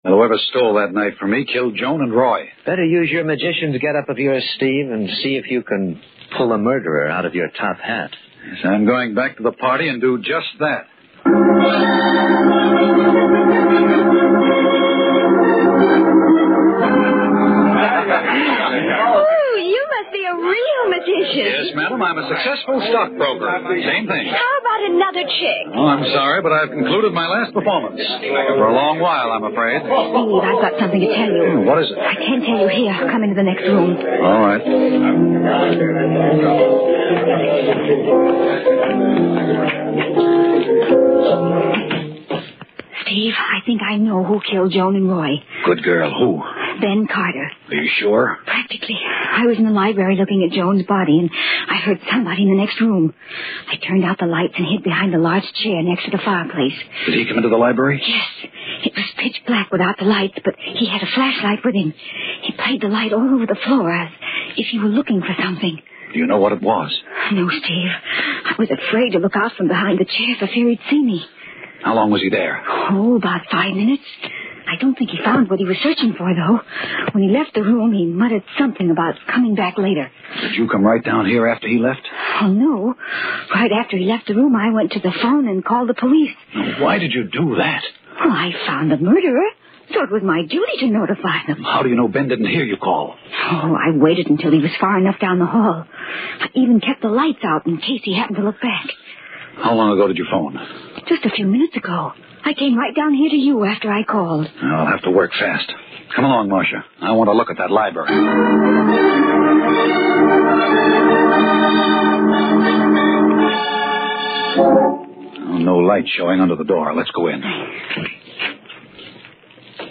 Tags: Radio Horror Mystery Radio Show The Haunting Hour